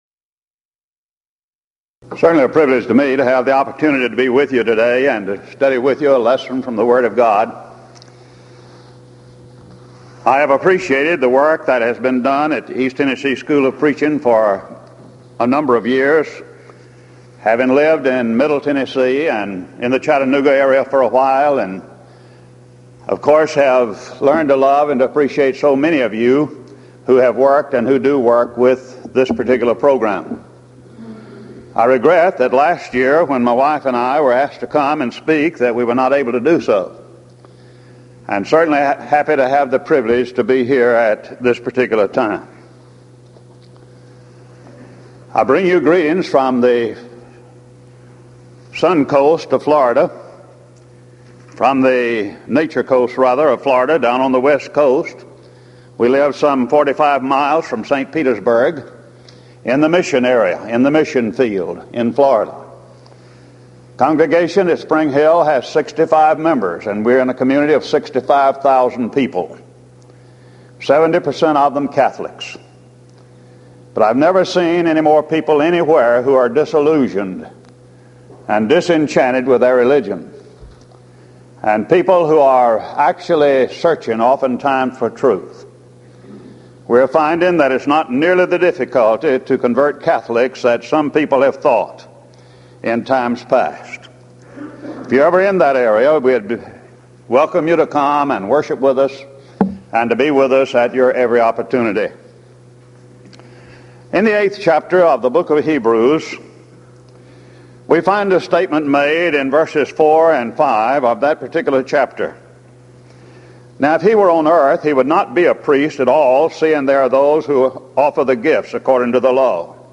Event: 1997 East Tennessee School of Preaching Lectures Theme/Title: Studies In The Book of Exodus
lecture